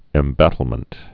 (ĕm-bătl-mənt, ĭm-)